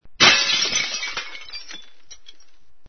VIDRIOROMPIENDISE.mp3